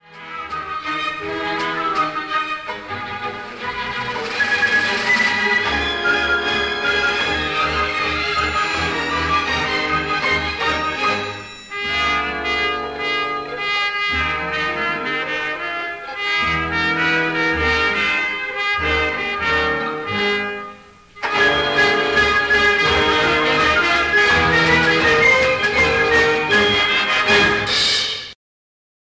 Orchestra
This orchestral suite is an adaptation of themes